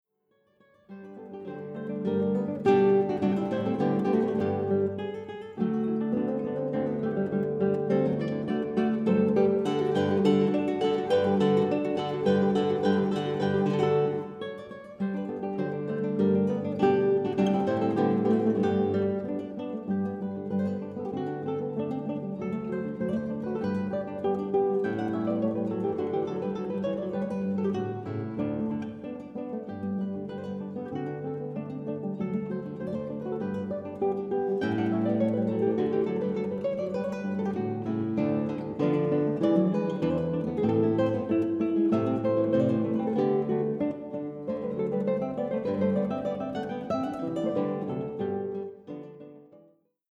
Zwei Gitarren